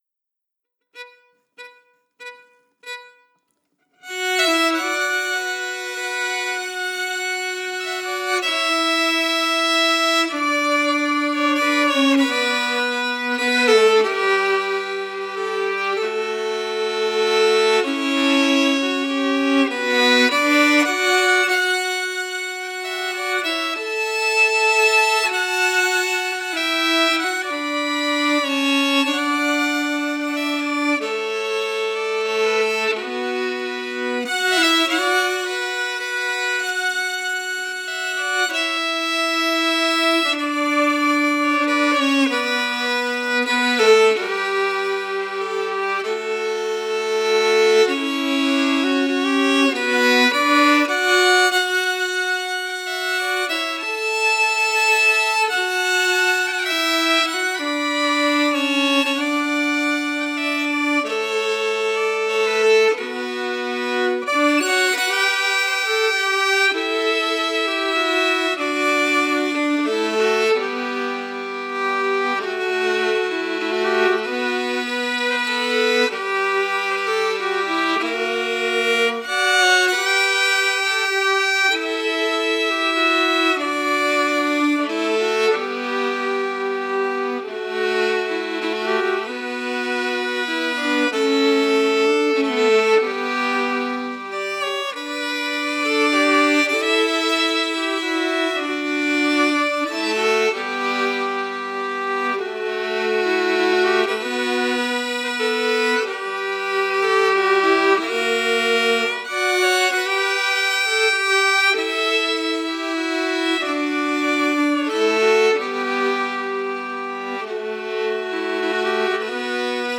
Key: Bm
Form: Slow Air
MP3: Harmony Emphasis
LakeChamplain-HarmonyEmphasis.mp3